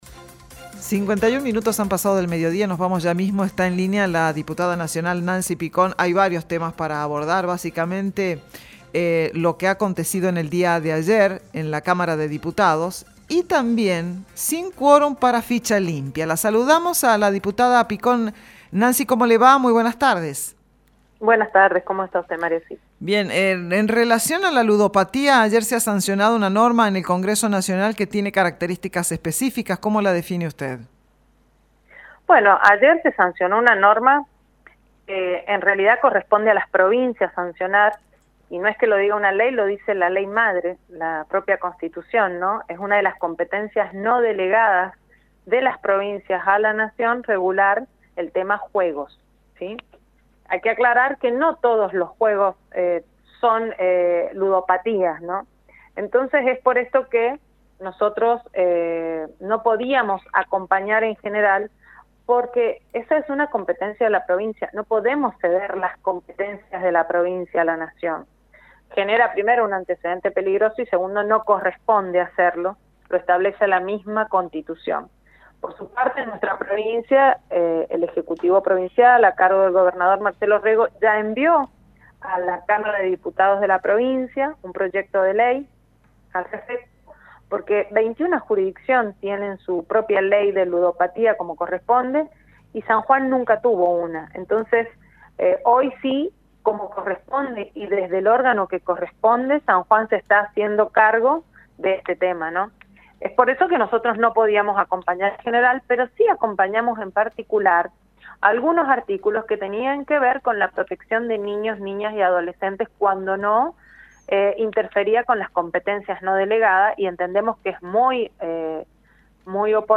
En comunicación con ESTACION CLARIDAD, la Diputada Nancy Picón, del bloque Producción y Trabajo, expresó su descontento con la ausencia de legisladores de algunos sectores políticos.